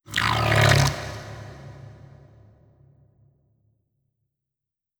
khloCritter_Male33-Verb.wav